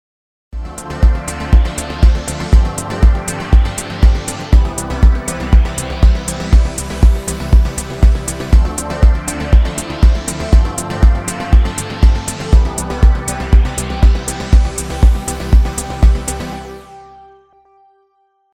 前回までは割りとアコースティックなサウンドに使っていましたが、今回はテクノっぽい感じのトラックに使ってみることにしました。
まずは、何もかかっていない（といってもシンセ側でかかっちゃっているものもあるけど）サウンドを聴いてみましょう。